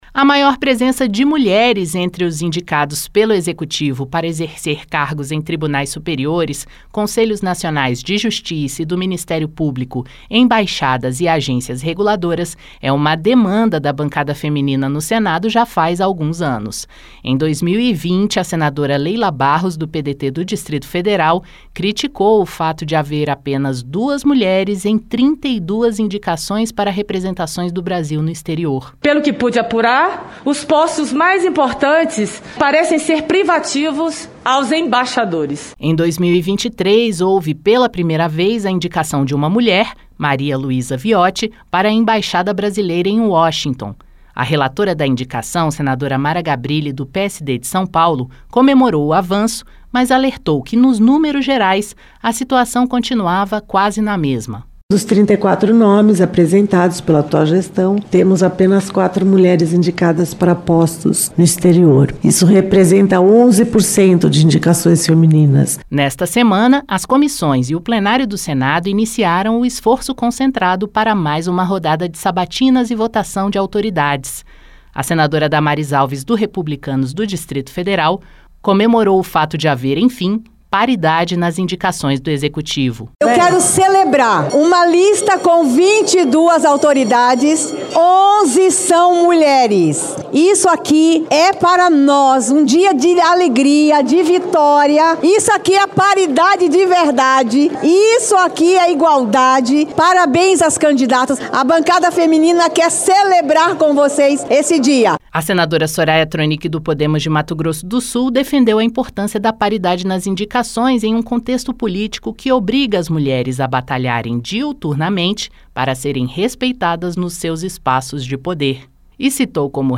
As comissões e o Plenário do Senado iniciaram o esforço concentrado para sabatinas e votação de autoridades. A senadora Damares Alves (Republicanos-DF) comemorou a paridade nas indicações do Executivo. Já a senadora Soraya Thronicke (Podemos-MS) ressaltou a importância da paridade, ao citar postagem do secretário de defesa norte-americano, Pete Hegseth, em apoio à opinião de que mulheres não deveriam votar.